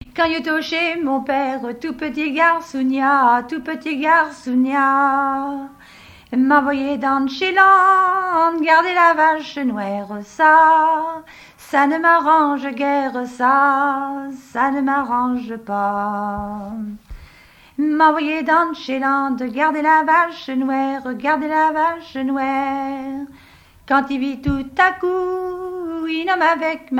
Témoignages sur le mariage et chansons traditionnelles
Pièce musicale inédite